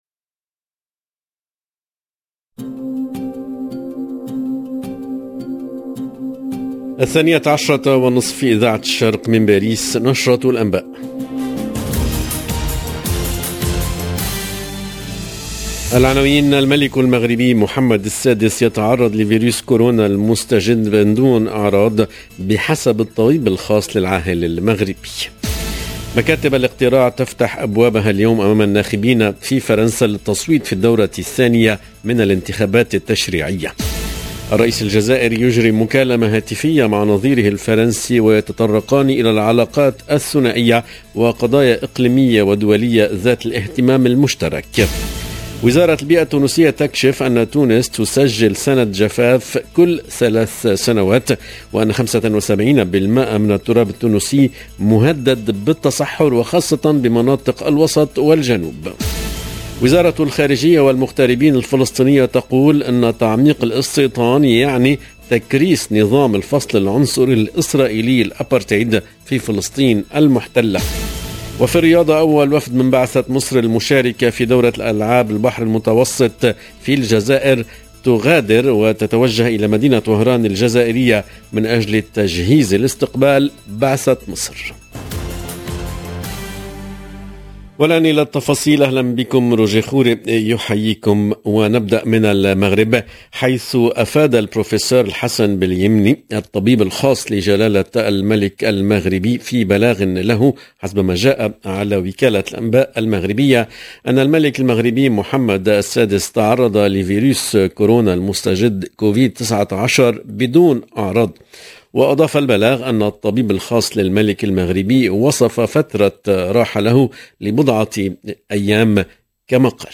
LE JOURNAL EN LANGUE ARABE DE MIDI 30 DU 19/06/22